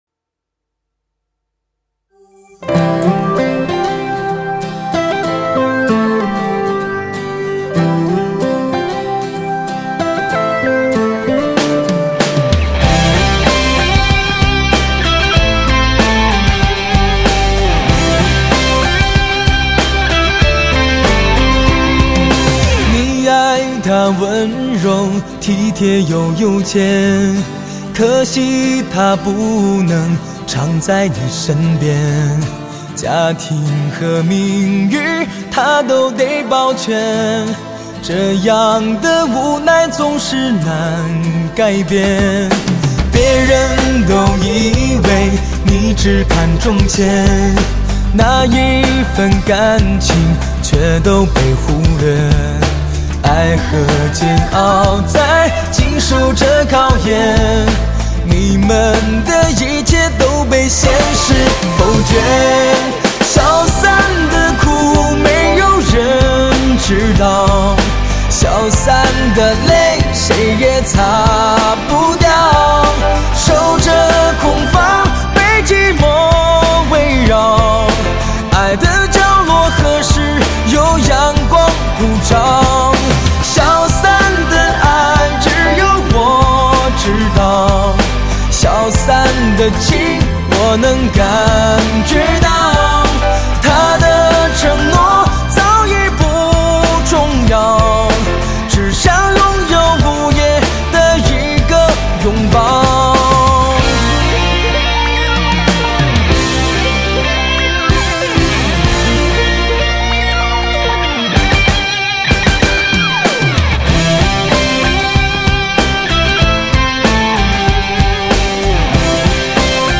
十三首缠绵感人的歌声